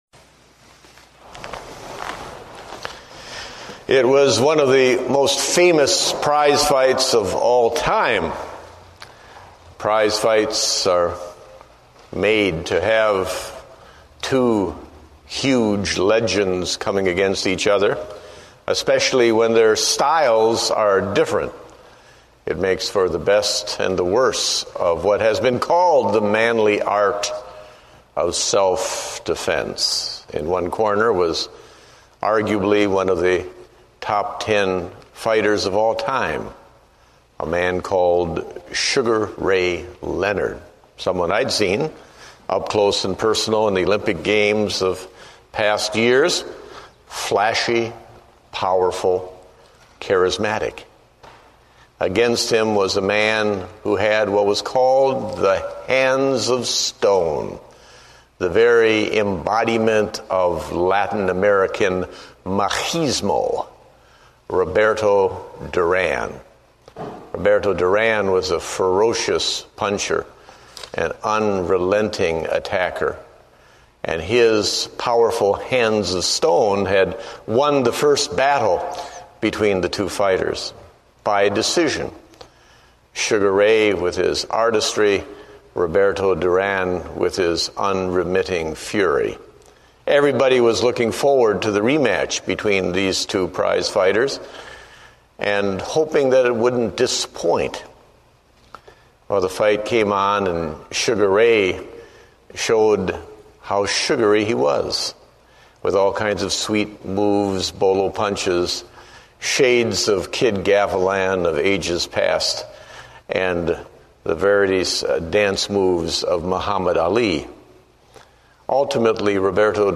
Date: June 22, 2008 (Evening Service)